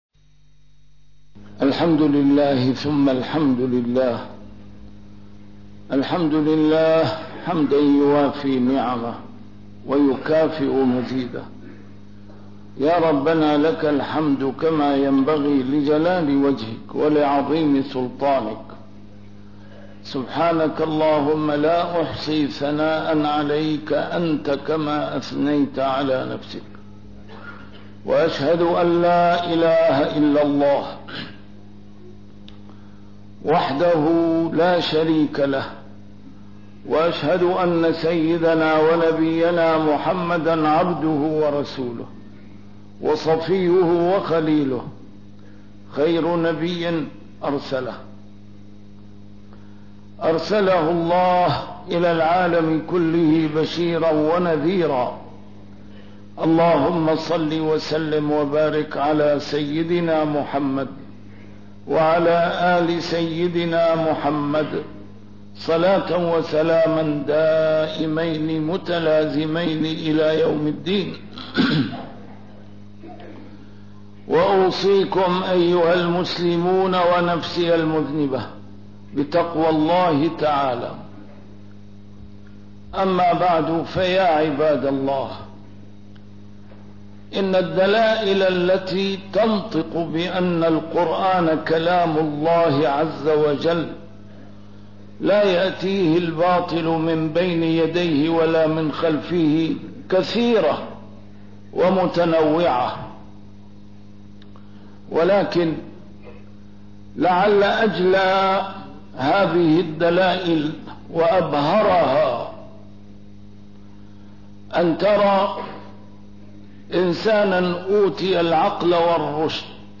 نسيم الشام › A MARTYR SCHOLAR: IMAM MUHAMMAD SAEED RAMADAN AL-BOUTI - الخطب - وما نرسل بالآيات إلا تخويفا